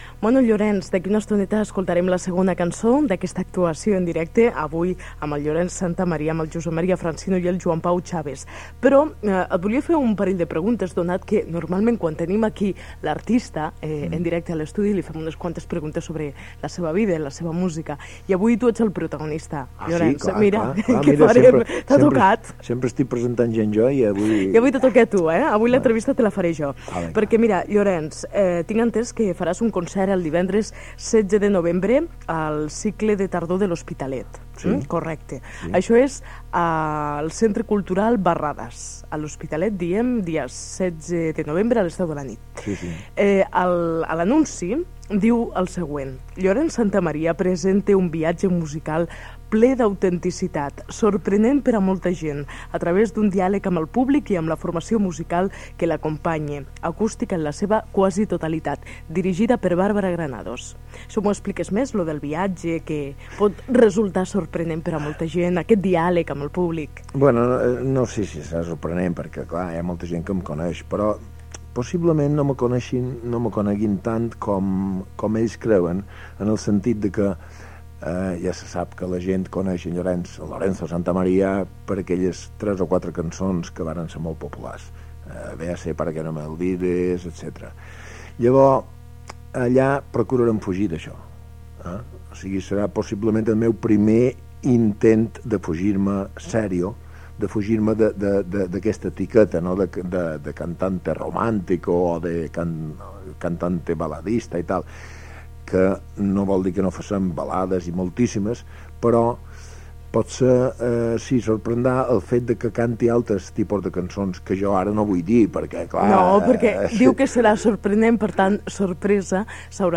Entrevista al cantant Llorenç Santamaria, col·laborador del programa
Entreteniment